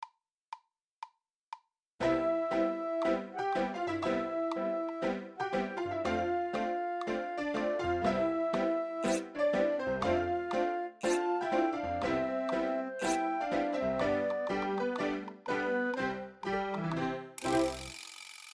軽快、最後オチ がある感じ